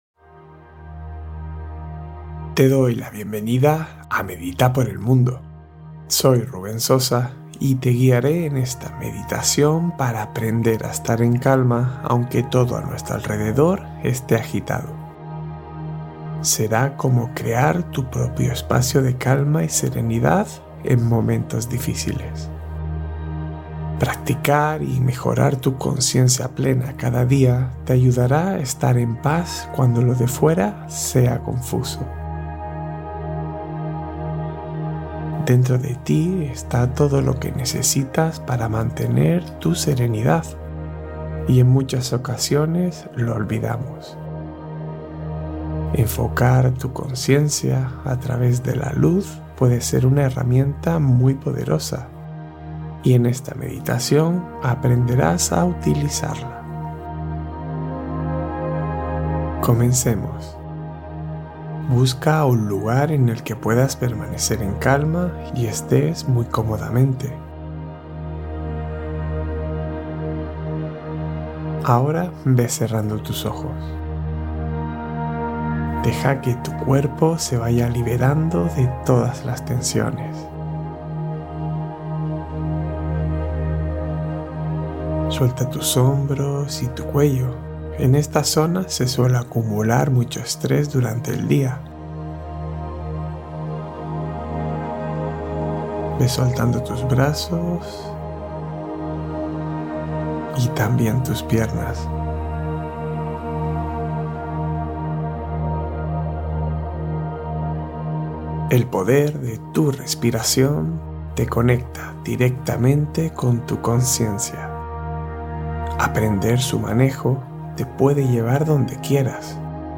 Llama Violeta: Meditación para Purificación y Luz Interior